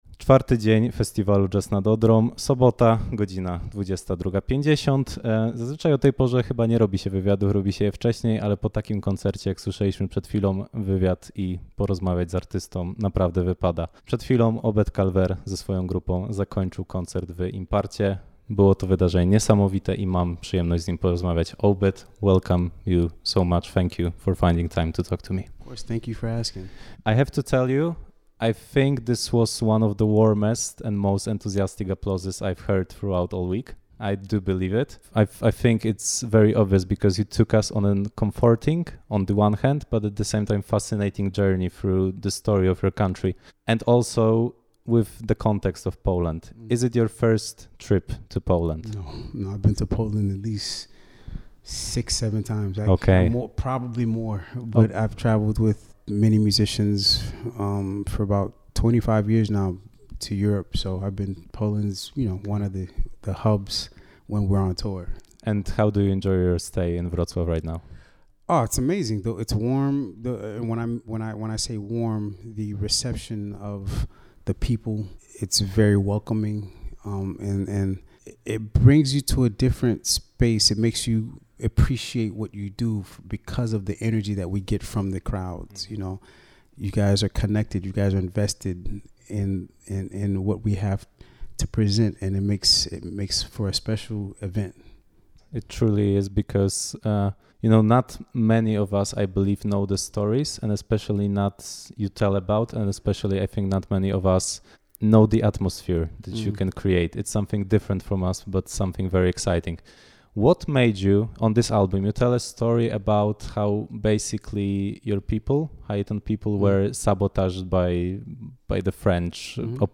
W krótkiej rozmowie po koncercie Calvaire opisał mi szeroko , dlaczego Haiti do dzisiaj nie może podnieść się z ogromnej biedy.